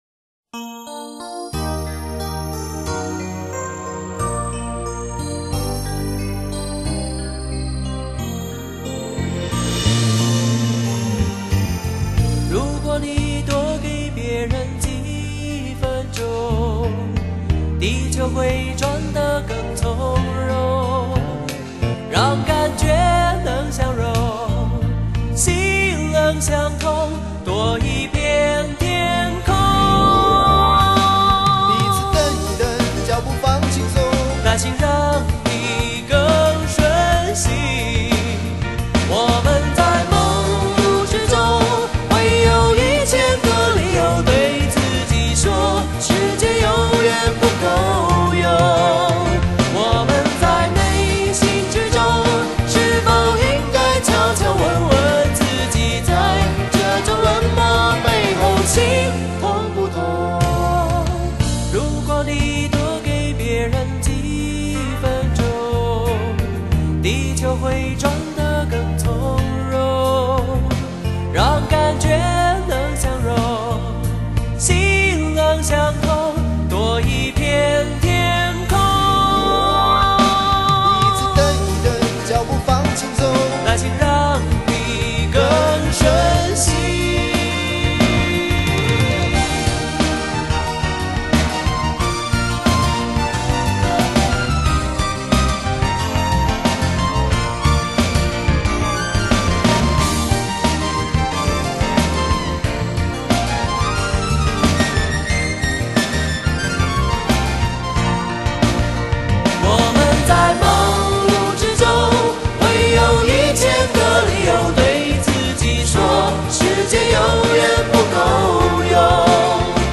這張專輯是該企業各年度電視廣告配曲